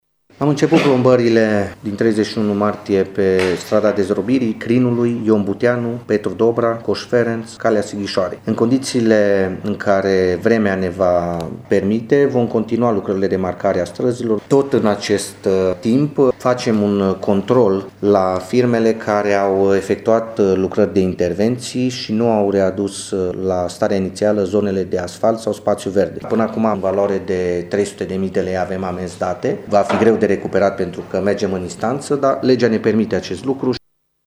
Viceprimarul Claudiu Maior a mai menționat și sancționarea firmelor care sparg asfaltul pentru a repara sau înlocui țevi dar lasă gropi în urmă: